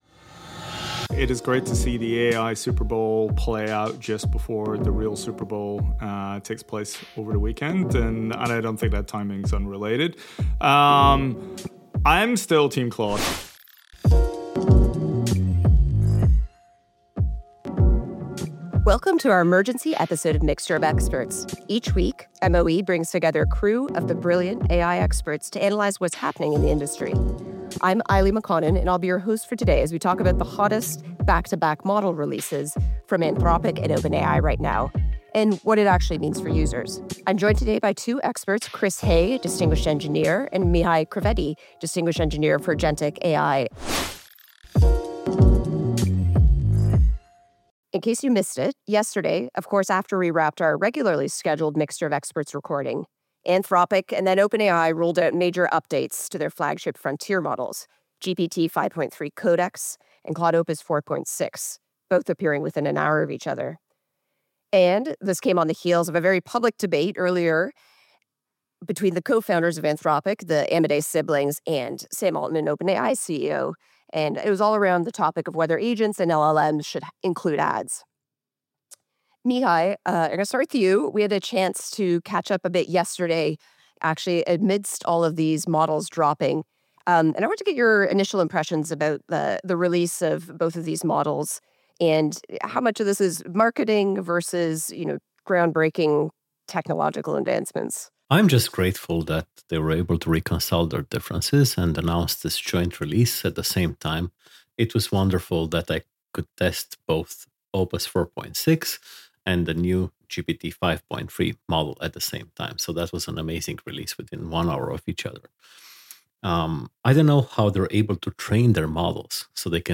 Our experts dissect both models. Which one actually performs better for coding tasks? Then, we unpack what these releases reveal about the intensifying battle for enterprise AI.